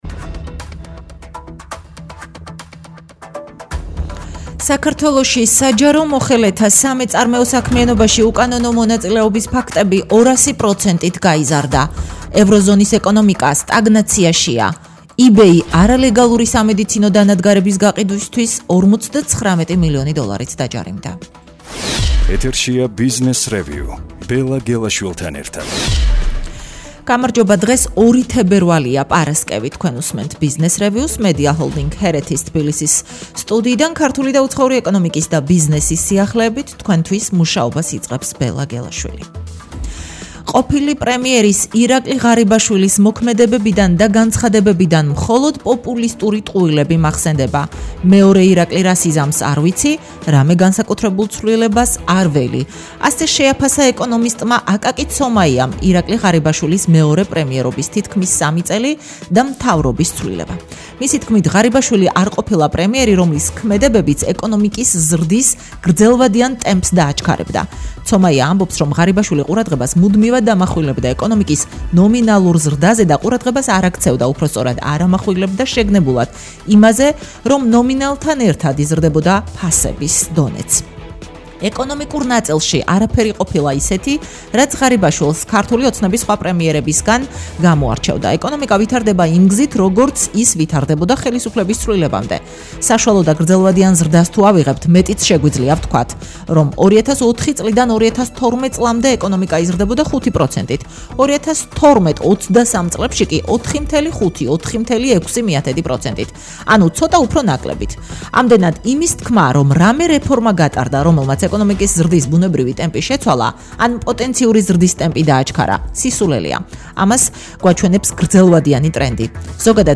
რადიოგადაცემა